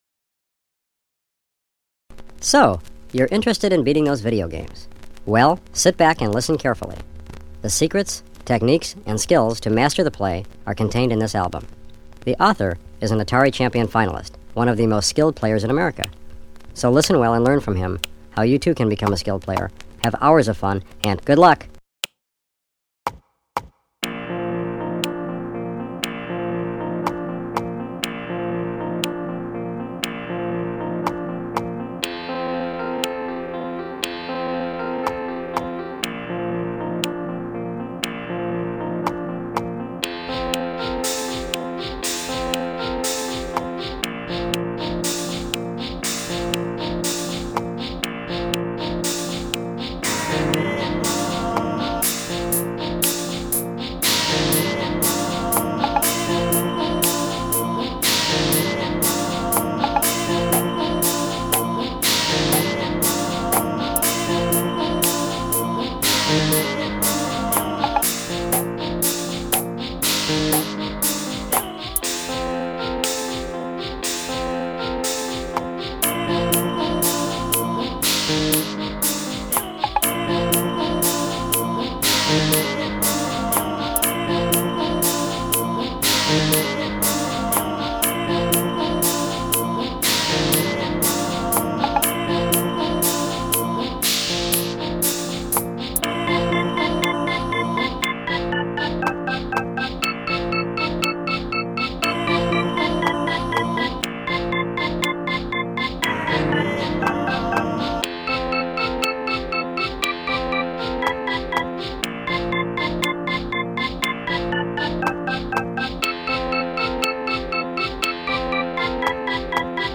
pianola, piatti